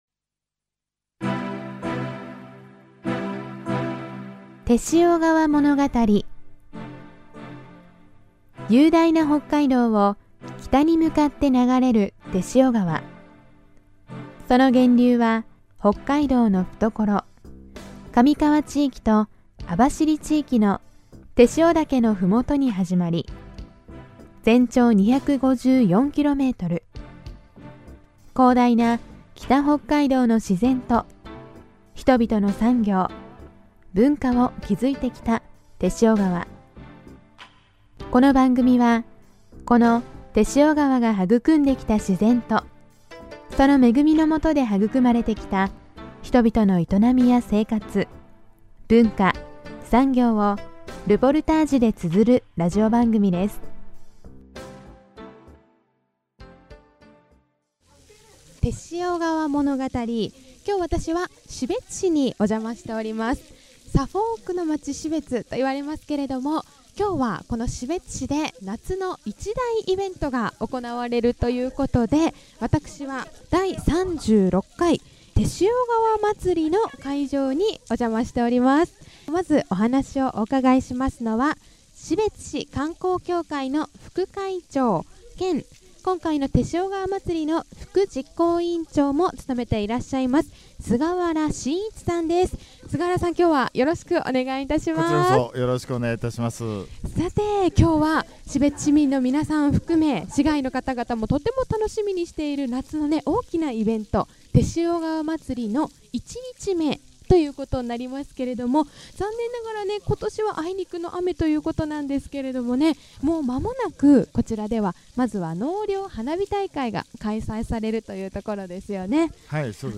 今回のものがたりでは、士別市で毎年市民に親しまれている夏の一大イベント「しべつ天塩川まつり」の会場で